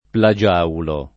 plagiaulo
[ pla J# ulo ]